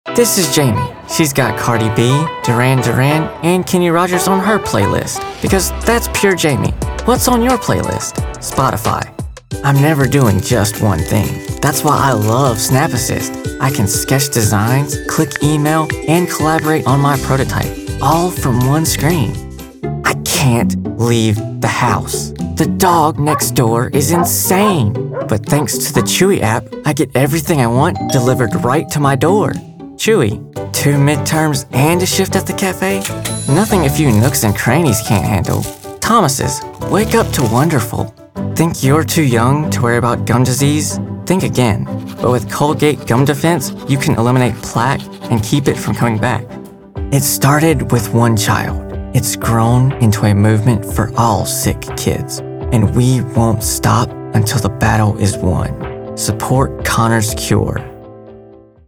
Male Voice Over Talent
Commercials
With a voice described as warm, respectful, and genuinely caring, I specialize in creating audio experiences that leave a lasting impression.